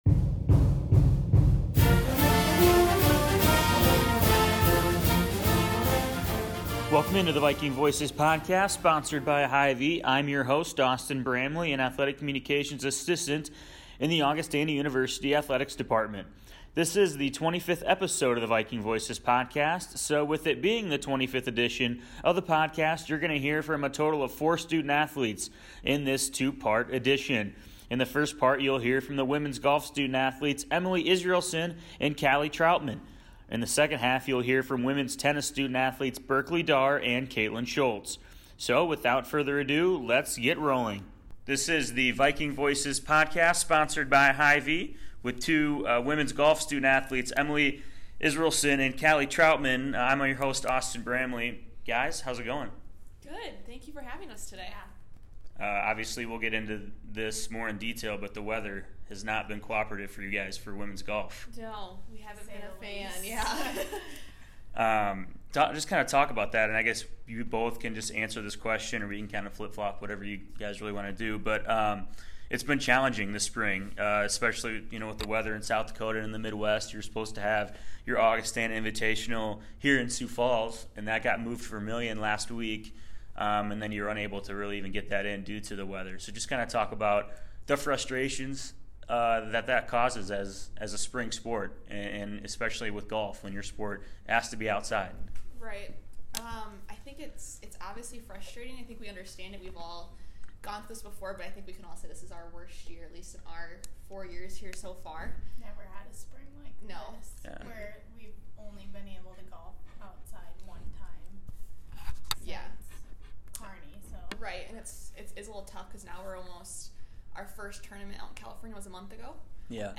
Episode 25 - Student-Athletes from Women’s Golf and Women’s Tennis Join the Viking Voices Podcast